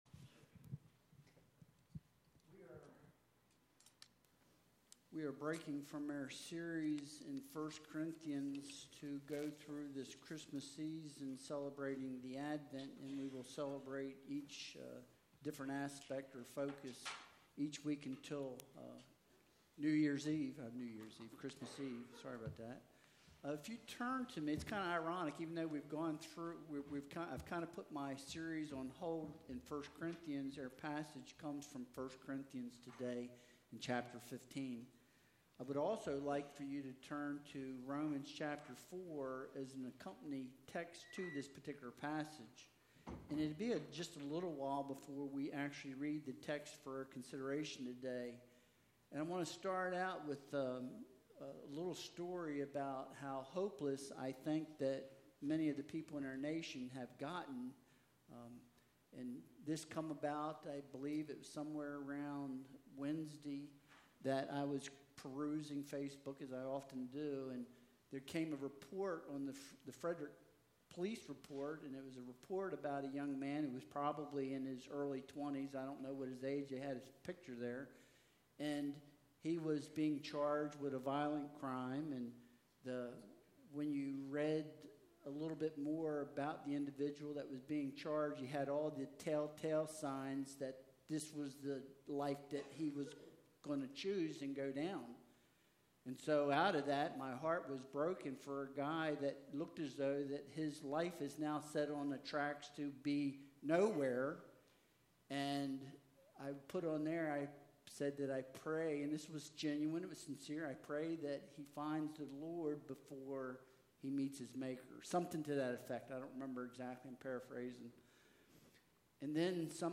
1 Corinthians 15.12-19 Service Type: Sunday Worship Service Download Files Bulletin Topics